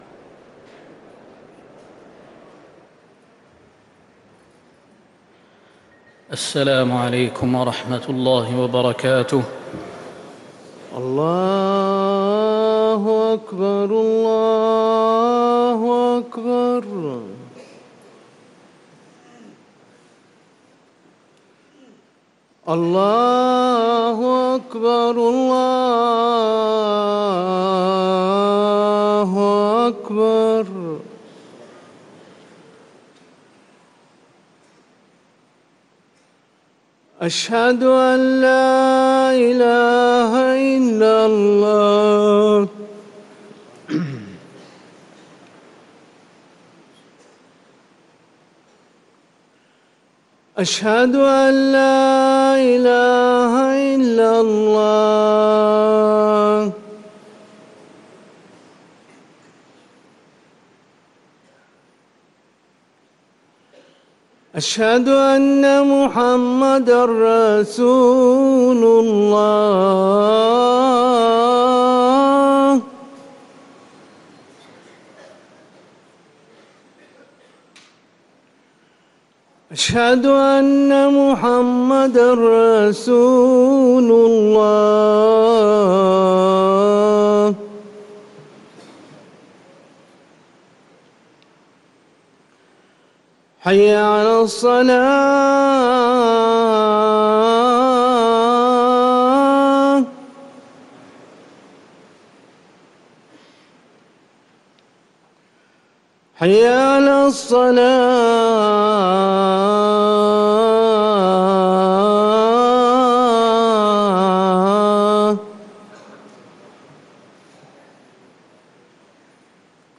أذان الجمعة الثاني
ركن الأذان